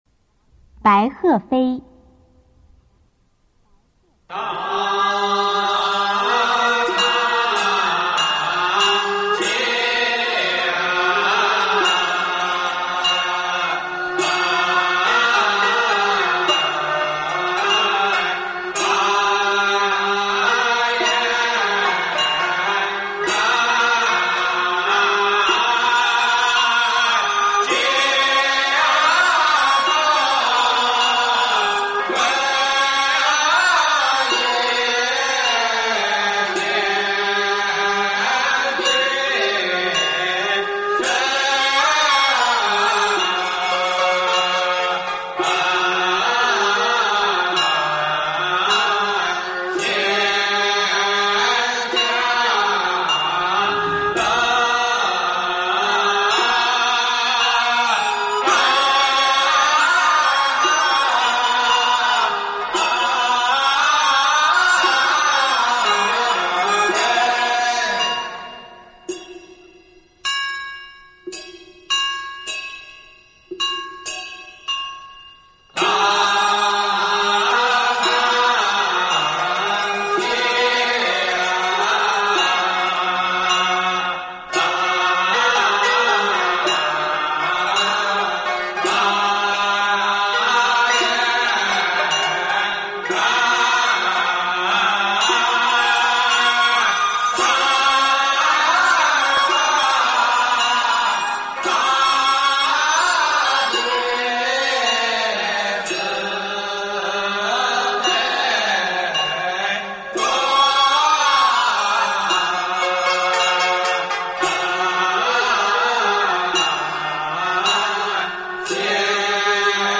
中国道教音乐 全真正韵 白鹤飞
简介：开经前诵经、拜忏常用，赞美圣真的不可思议功德，末句结以仙翁骑白鹤翱翔天空，在悠扬的仙乐中降临坛场。
此曲表达道家飘然洒脱的超然境界，集喧嚣和空灵于一身。